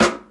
Snare (Burger).wav